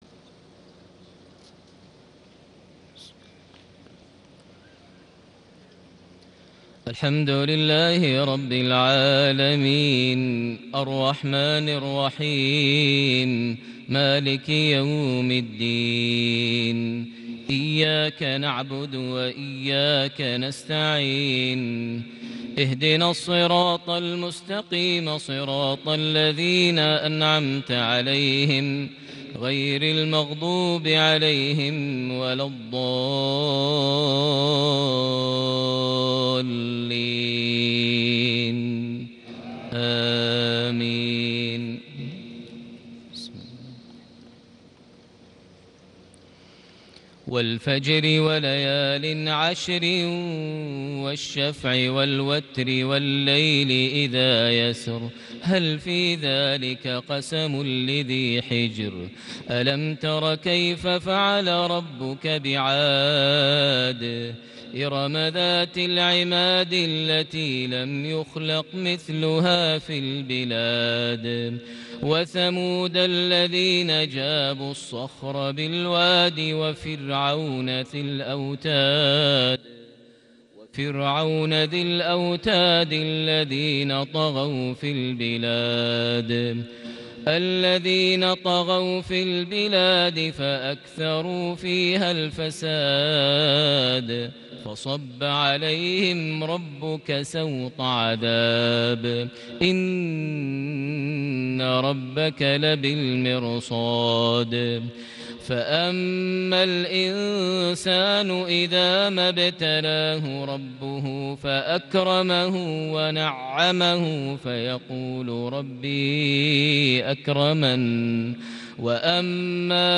صلاة العشاء ١٩ ذو القعدة ١٤٣٨هـ سورة الفجر > 1438 هـ > الفروض - تلاوات ماهر المعيقلي